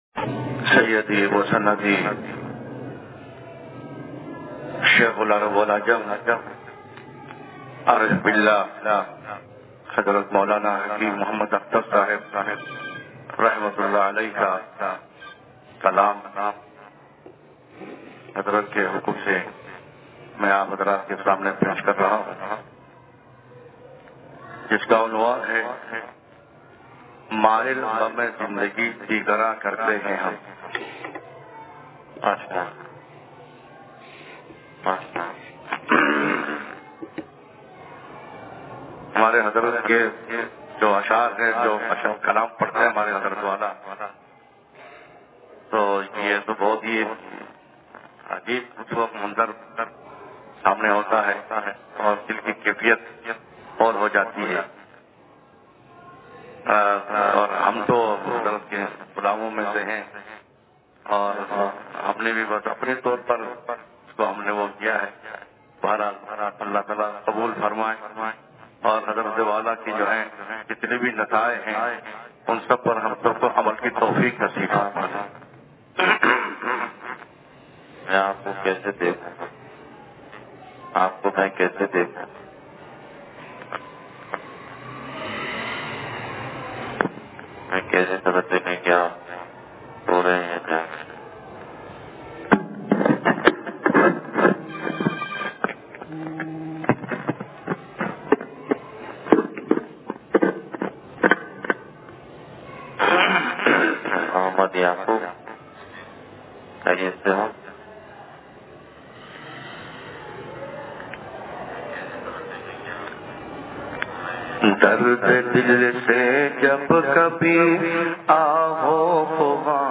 بیان انصاریہ مسجد لانڈھی